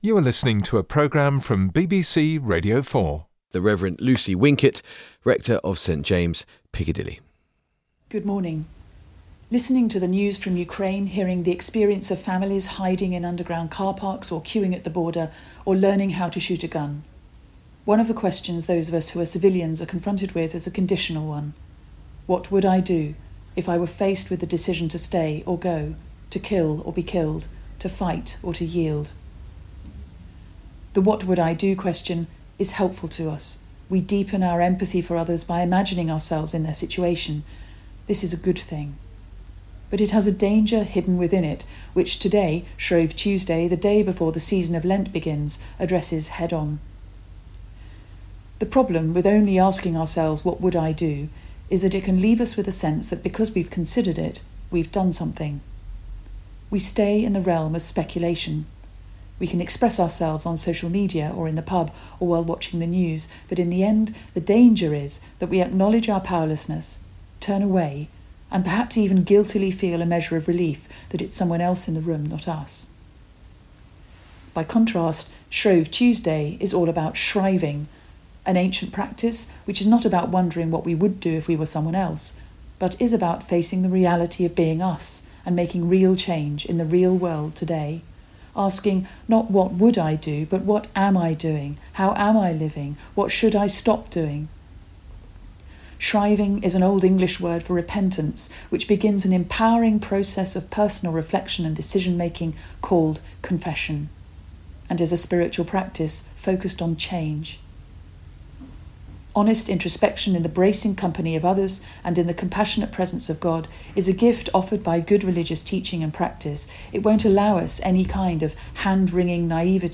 BBC Radio 4’s Religion & Spirituality podcast providing reflections from a faith perspective on issues and people in the news.